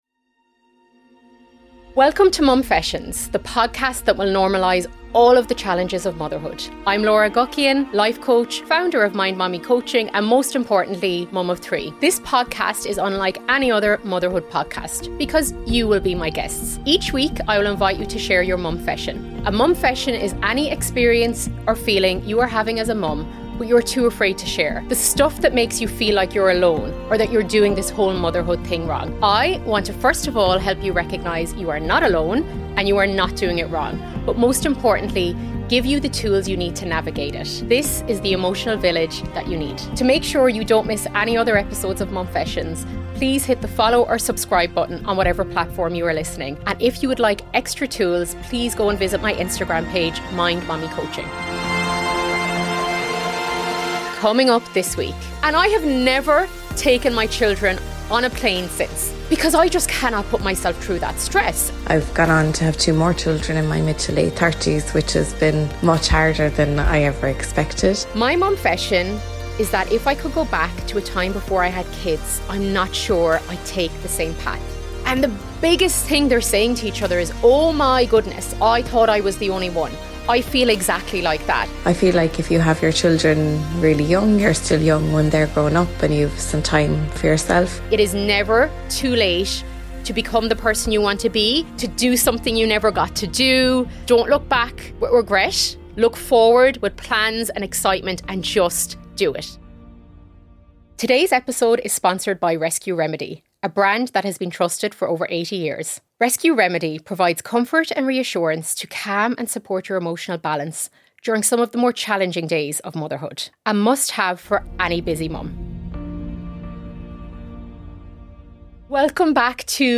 I am also joined by 2 x amazing Moms who share their Momfessions around Motherhood Regret and Hard Chapters of Motherhood.